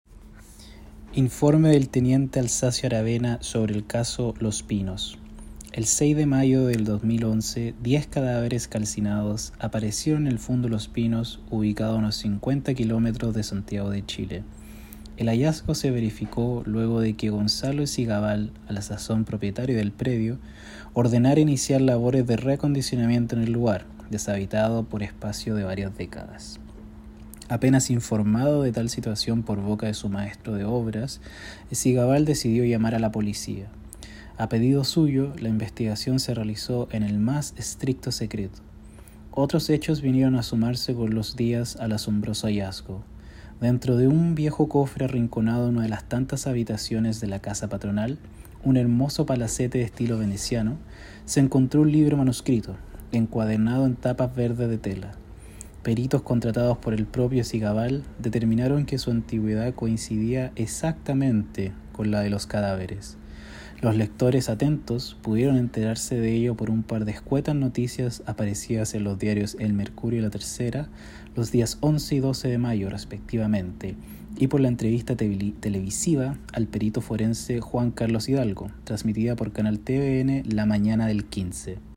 Hector Hernández Montecinos Escucha al autor leer un fragmento del cuento .